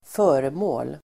Uttal: [²f'ö:remå:l]